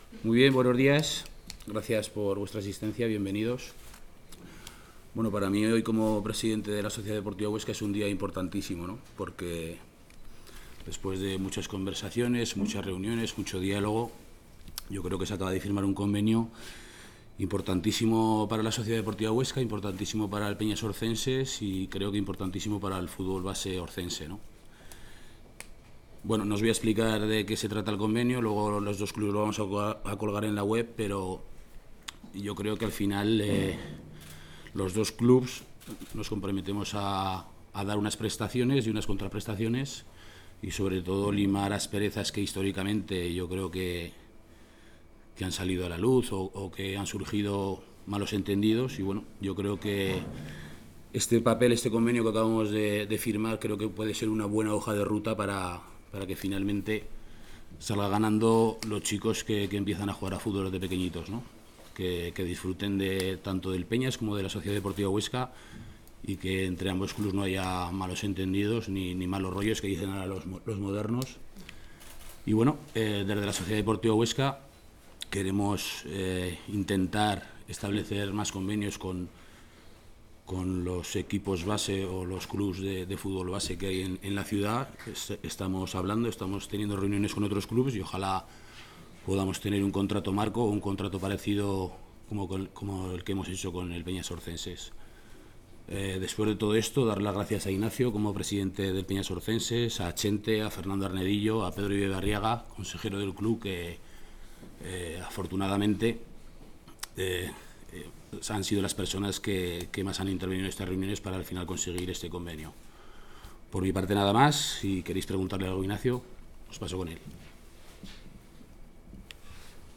Corte de sonido de la rueda de prensa posterior a la firma del Convenio